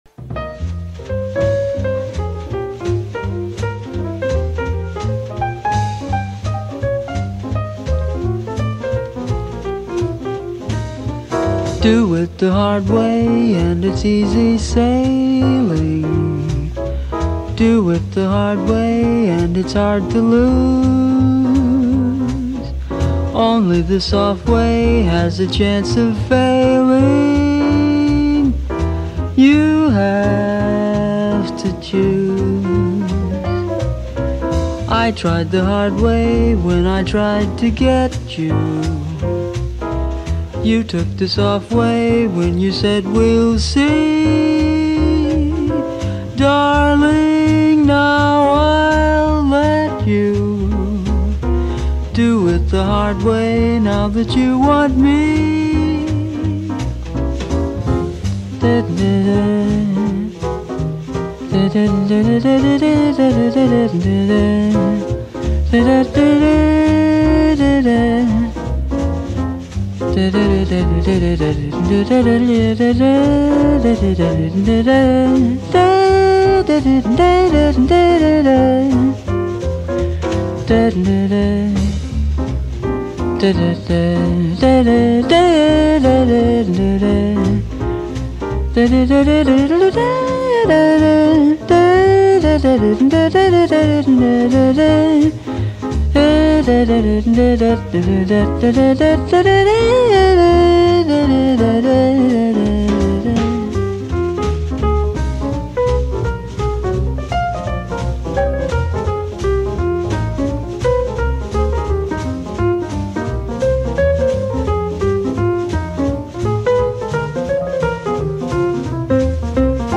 Cool Jazz, Vocal Jazz